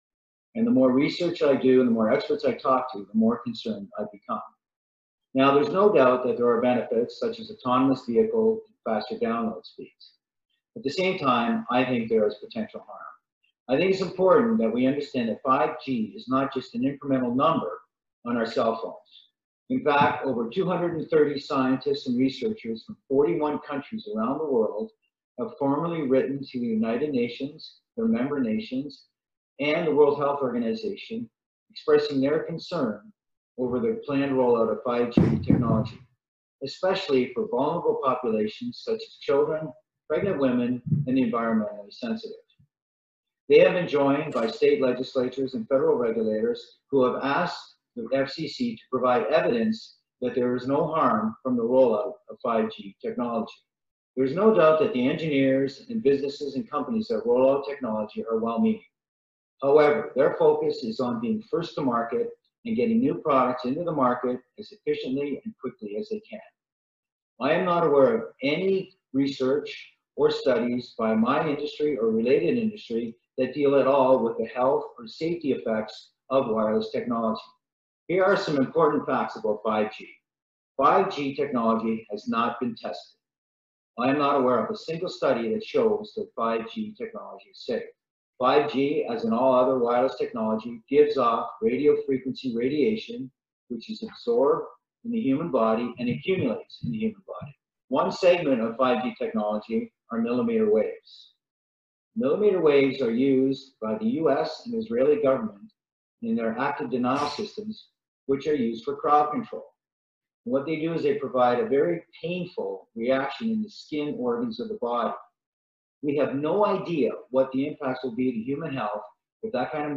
ההרצאה